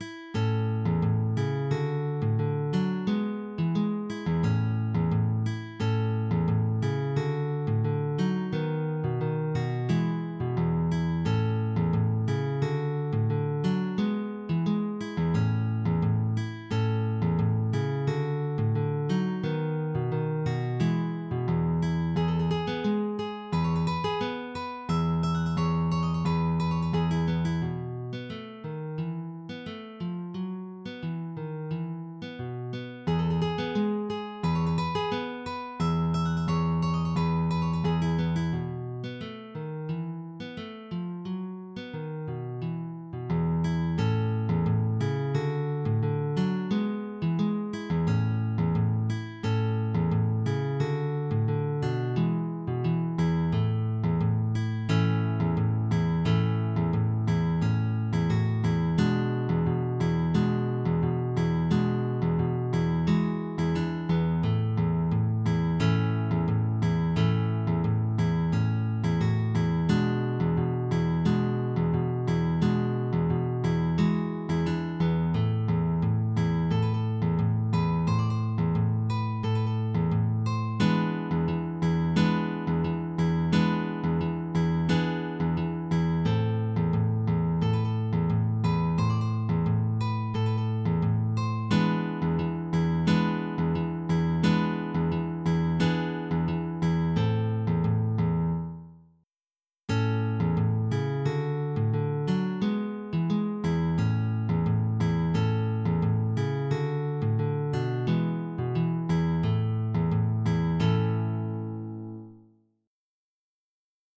bosch-10-easy-pieces-no10-tango-flamenco.mid.mp3